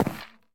creaking_heart_step2.ogg